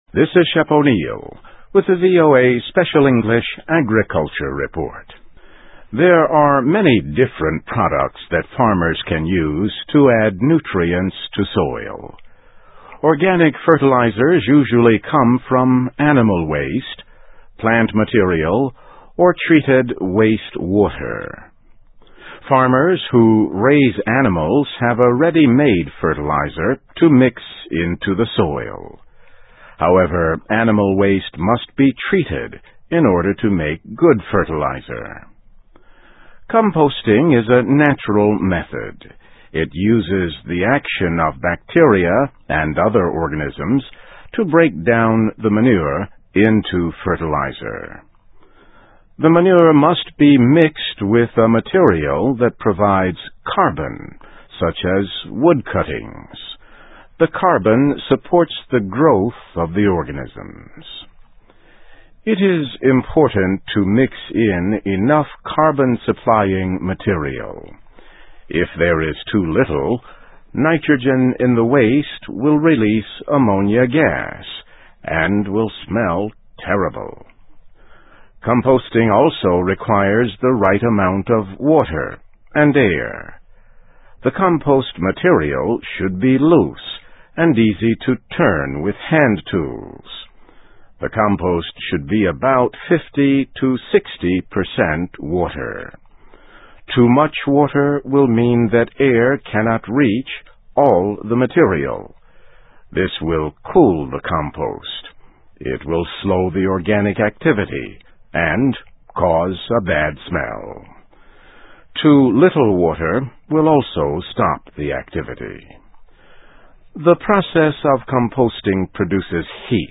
Food for Crops: How to Get the Most From Organic Fertilizer (VOA Special English 2005-08-22)